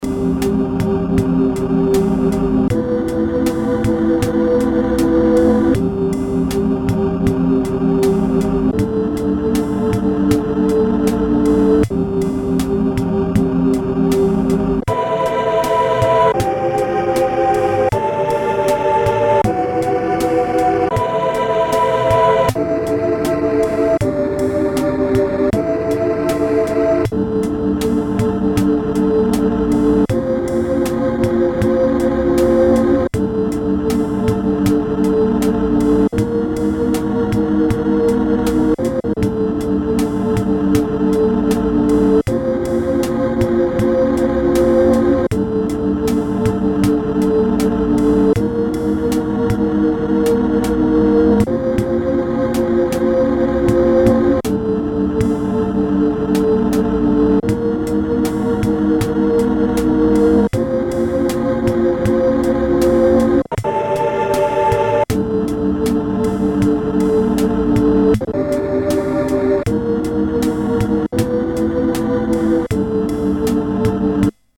Portable keyboard based on FM synthesis + lo-fidelity sampling.
demo AUDIO DEMO
sample pad with FM drums
demo sample pitch tuning
demo vibrato
demo sampled speech
demo FM rhythm patterns